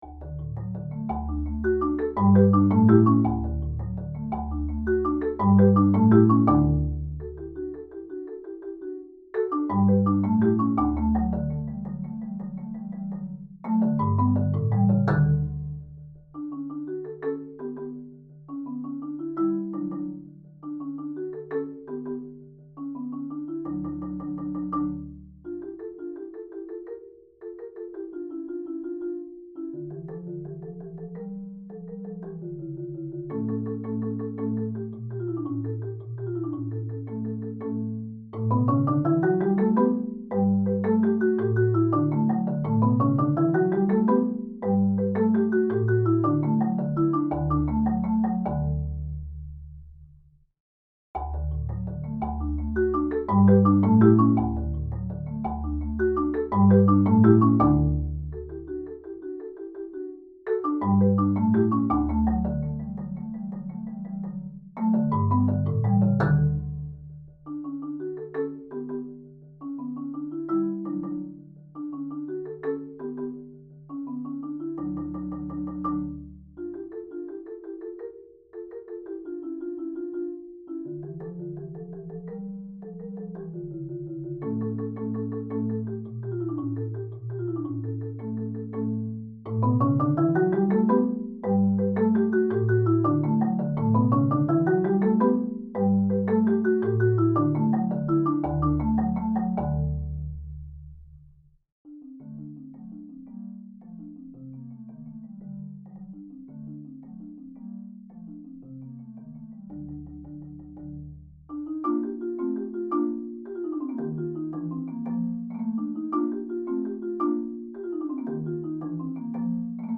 Genre: Marimba (4-mallet)
Marimba (5-octave)